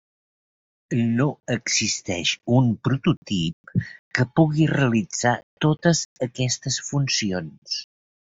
Pronounced as (IPA) [ˈto.təs]